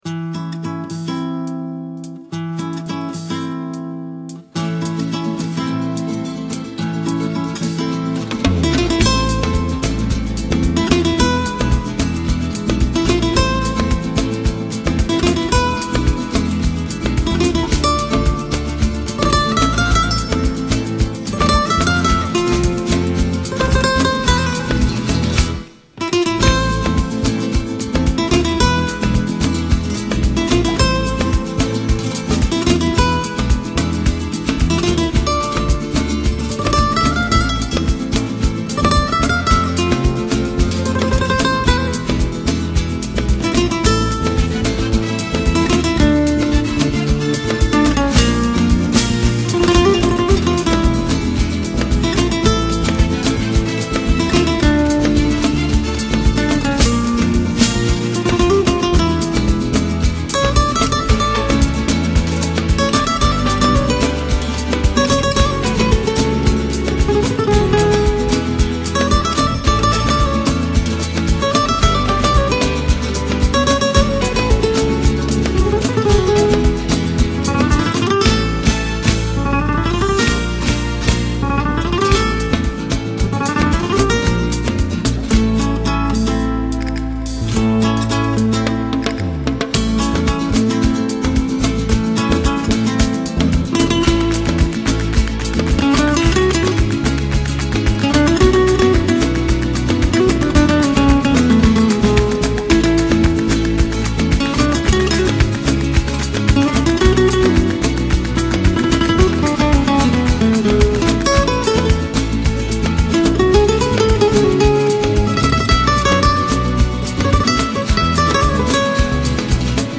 风格:发烧音乐 新音乐